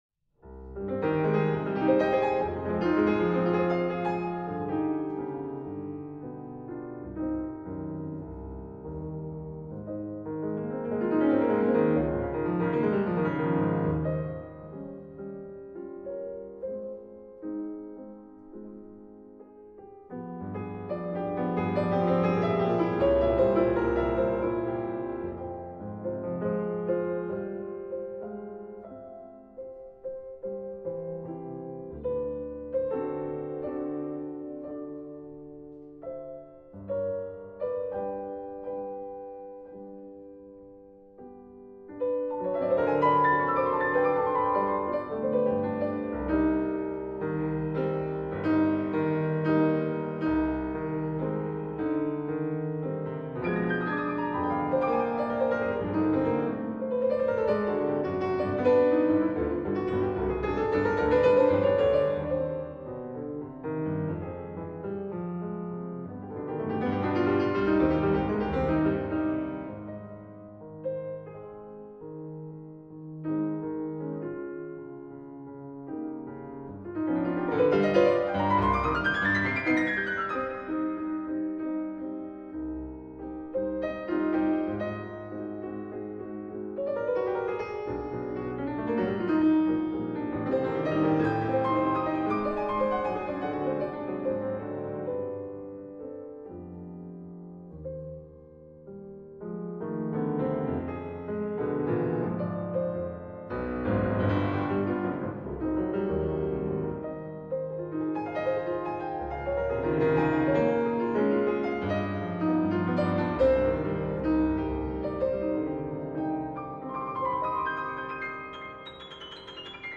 Se trata de una pieza para piano